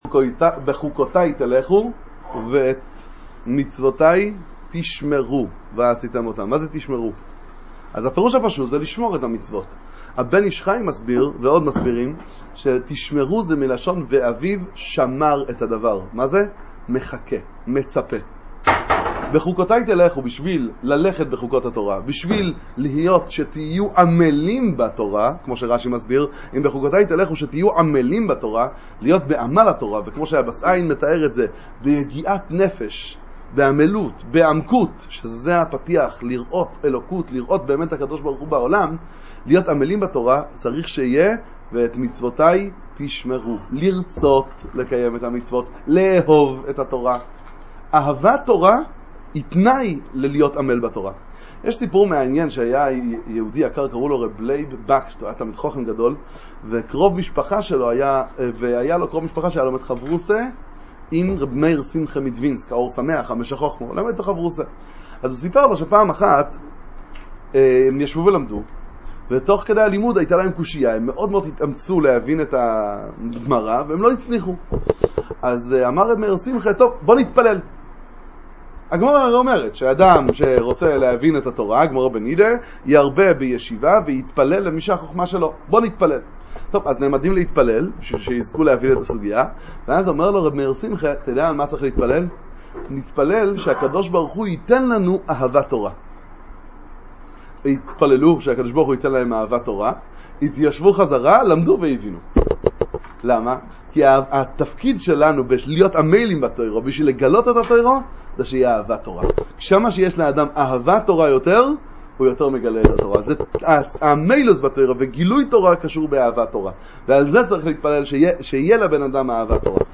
דבר תורה קצר לצפיה על פרשת בחוקותי